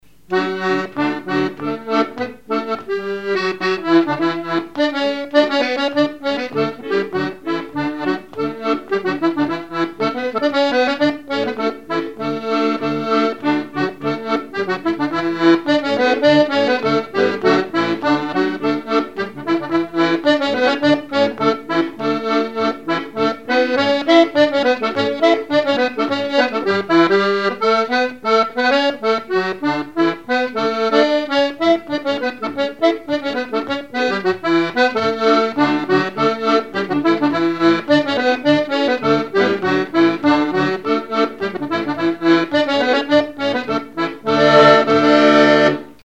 Chants brefs - A danser
danse : scottish (autres)
danse : scottich trois pas
instrumentaux à l'accordéon diatonique
Pièce musicale inédite